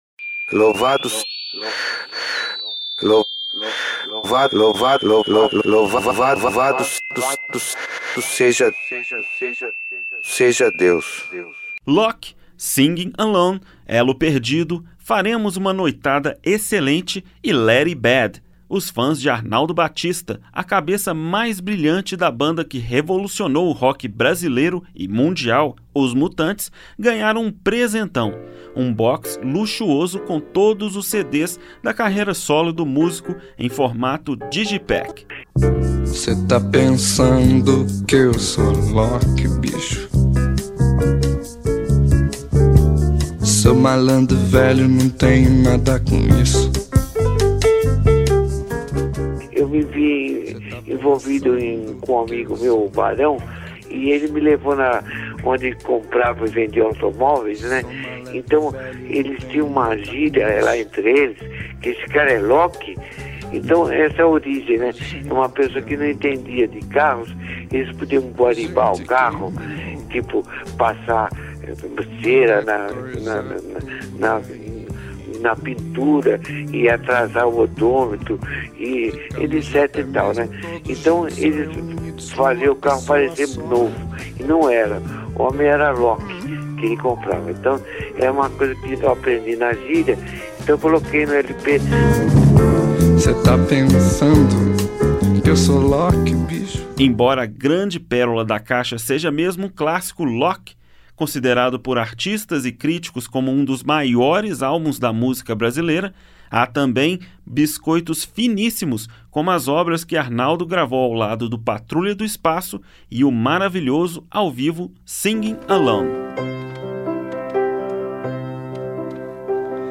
Gênero: Blues.